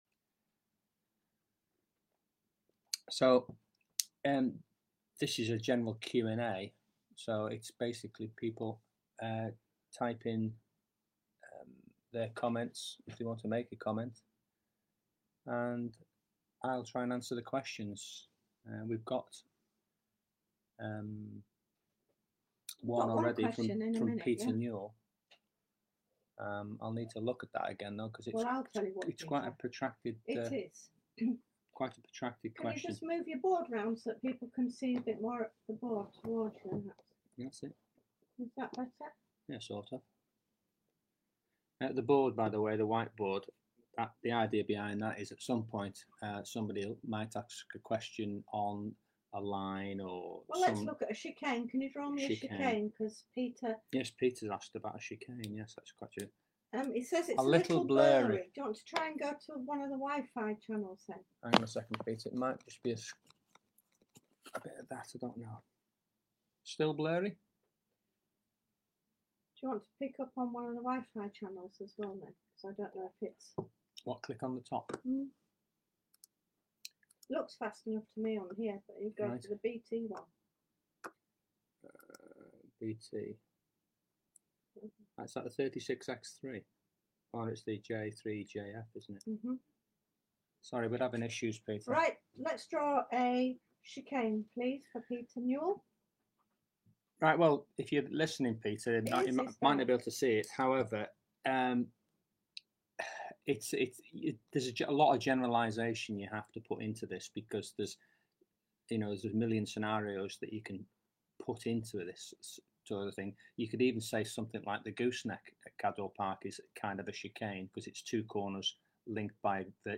Informal online Q&A chat #2